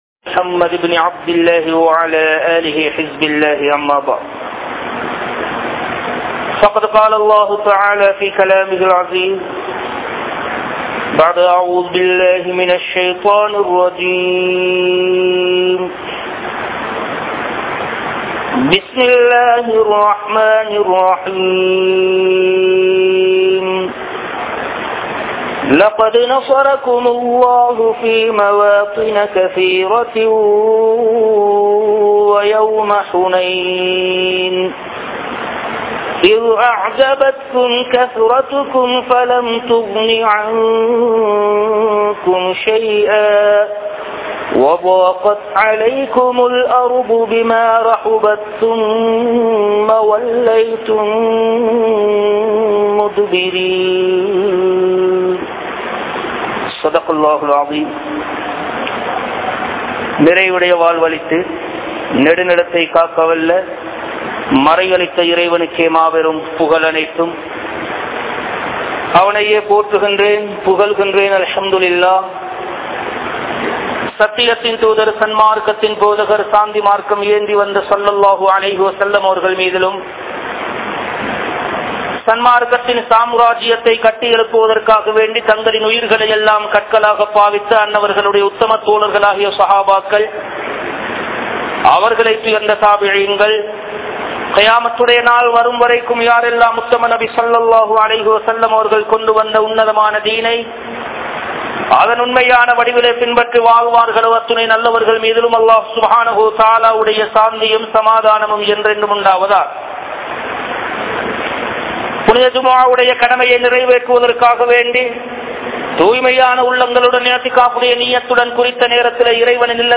Aatsi Maattrathitku Kaaranam Yaar? (ஆட்சி மாற்றத்திற்கு காரணம் யார்?) | Audio Bayans | All Ceylon Muslim Youth Community | Addalaichenai
Kandy, Kattukela Jumua Masjith